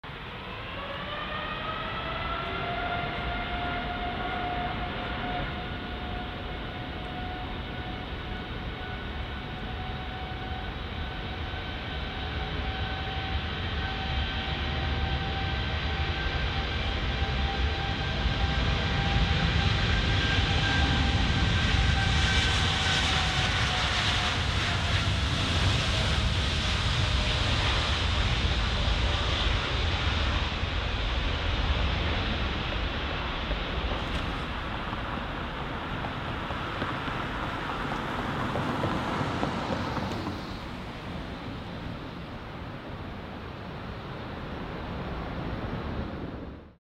Positioned at Mp3 Sound Effect Gatwick Airport (EGKK). Positioned at the threshold of Runway 26L, a Boeing 787 Dreamliner—its sleek, curved wings flexed slightly upward—is holding short, awaiting clearance.